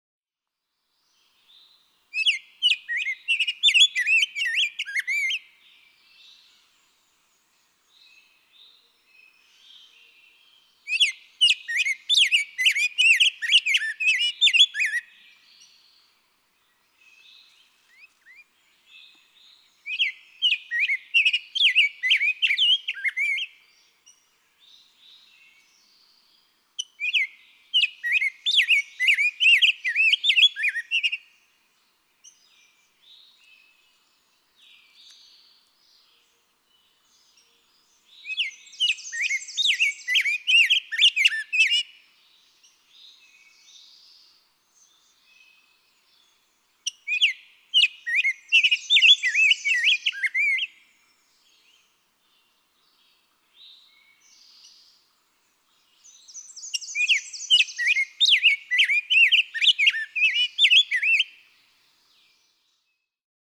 Rose-breasted grosbeak
Normal song, routinely spectacular.
Quabbin Park, Ware, Massachusetts.
098_Rose-breasted_Grosbeak.mp3